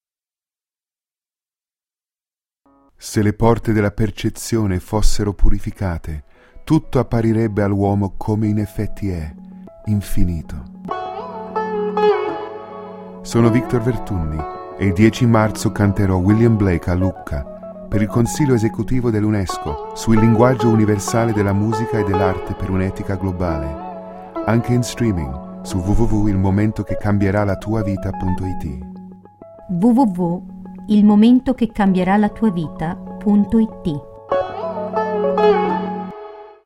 Spot: minimalisti, con poche repliche, su pochissime radio molto specifiche (quelle che la gente sceglie per ascoltare le parole).
Suono low fi: non vale la pena di spendere centinaia di euro per una qualità super hi fi: una onesta qualità digitale è sufficiente, perchè la radio è fruita quasi sempre con una qualità inferiore (una banda passante pessima) e la maggior parte degli ascoltatori ascolta in auto nell’orario dei pendolari, (quindi col rumore del motore acceso);